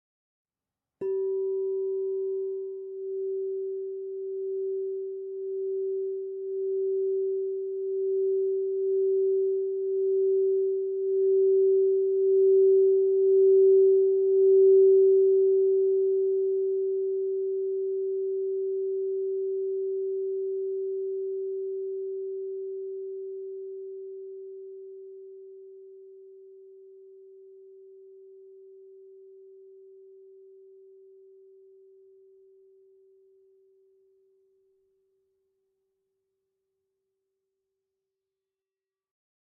Meinl Sonic Energy 10" Marble Crystal Singing Bowl G4, Light Blue, 432 Hz, Throat Chakra (MCSB10G)
The Meinl Sonic Energy Marble Crystal Singing Bowls made of high-purity quartz create a very pleasant aura with their sound and design.